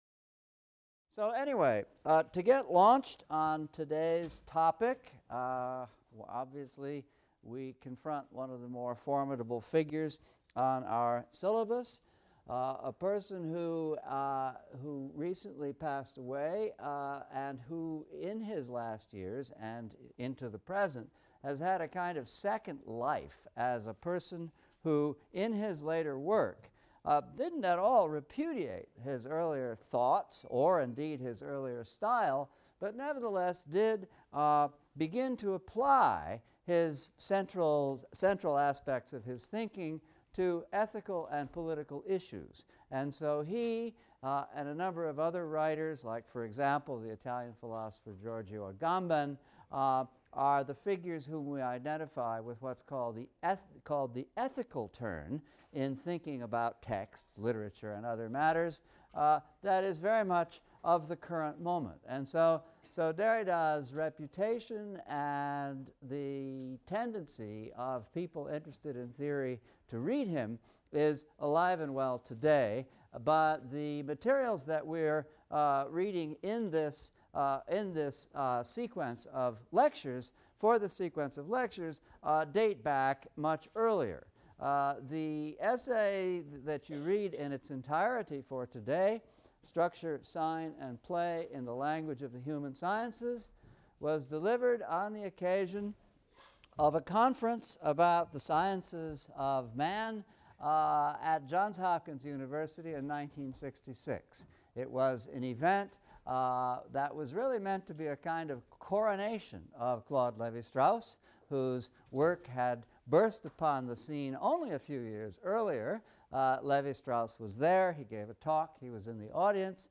ENGL 300 - Lecture 10 - Deconstruction I | Open Yale Courses